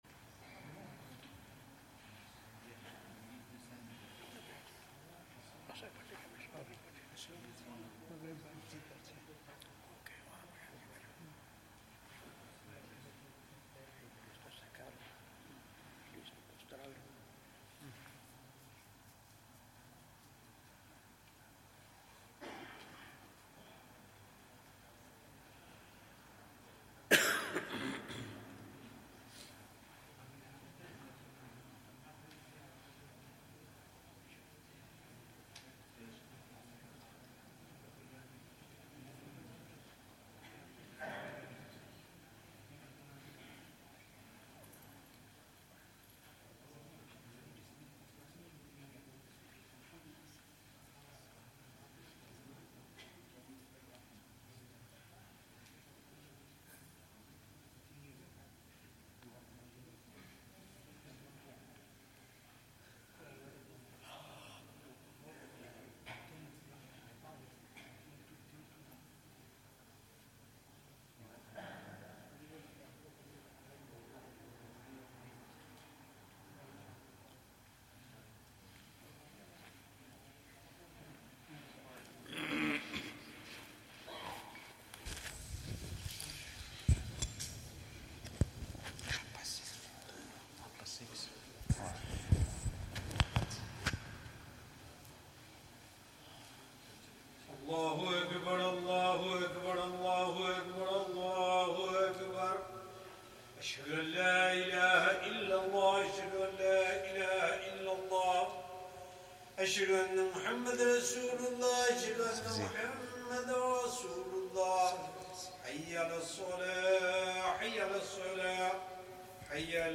Zakariyya Jaam'e Masjid, Bolton